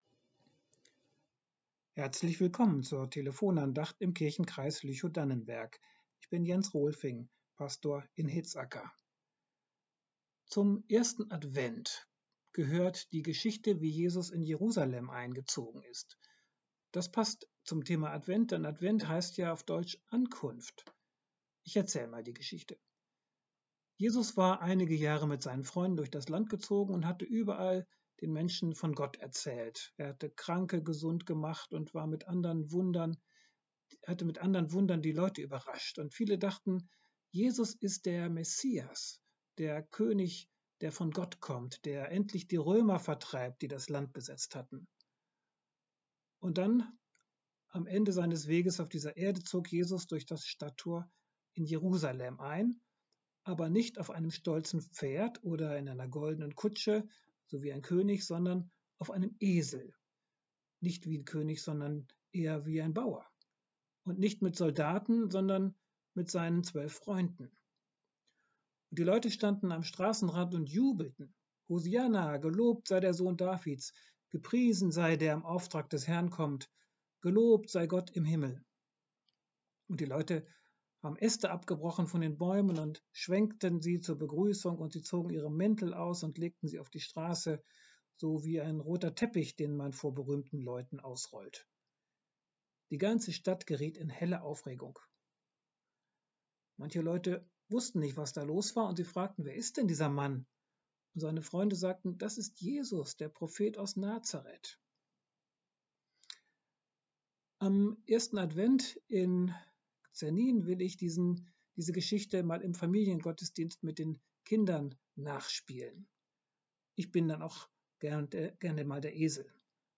Ein kluger Esel ~ Telefon-Andachten des ev.-luth. Kirchenkreises Lüchow-Dannenberg Podcast